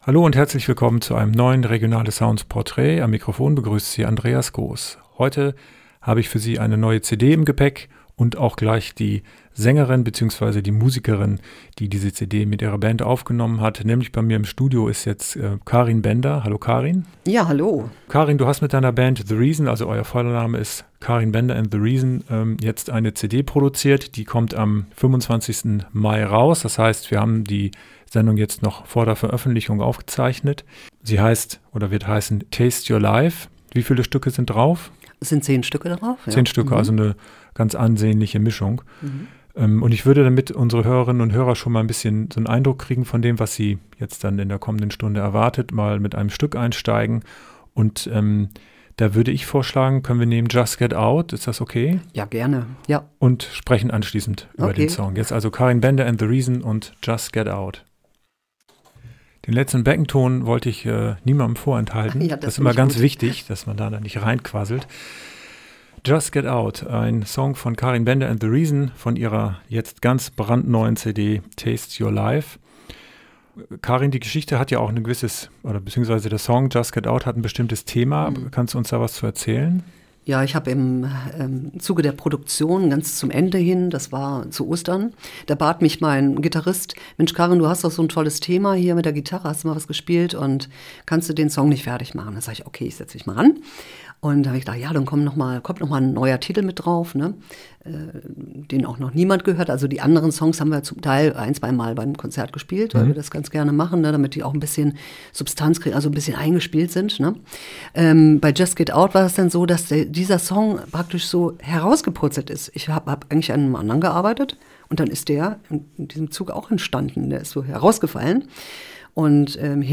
Hoffnung geben und das Texten als inneres Aufräumen: Interview